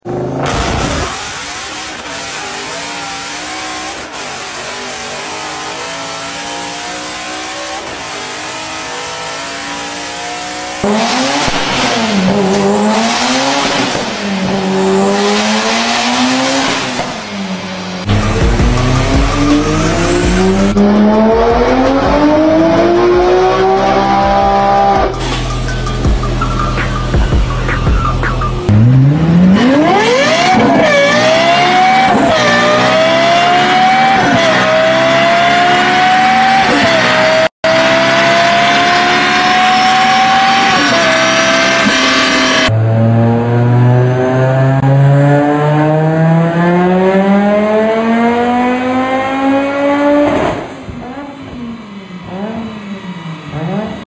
Top 5 Powerful Dyno Pulls sound effects free download